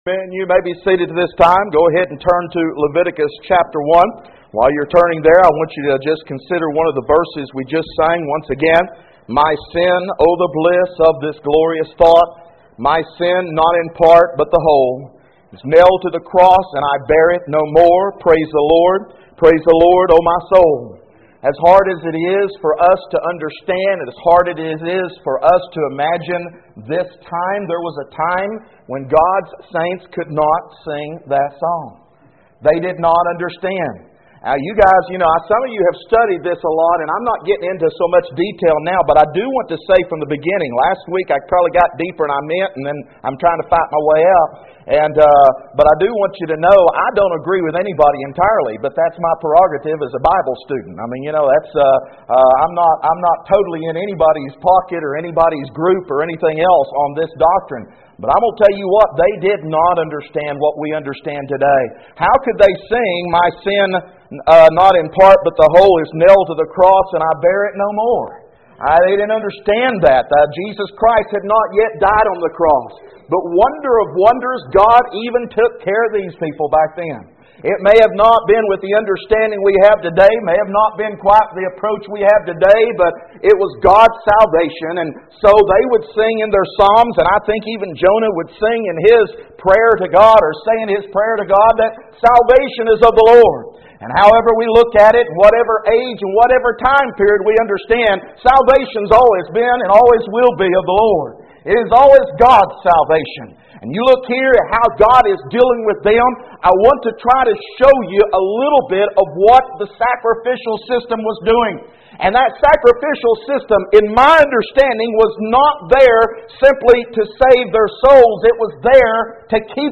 However, if this is all we get, we miss out on the miraculous blessings of death. Audio Sermon With Outline